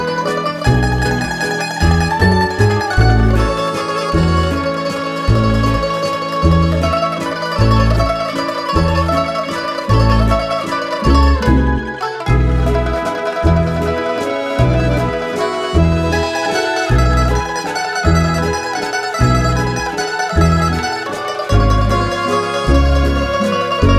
no Backing Vocals Crooners 3:05 Buy £1.50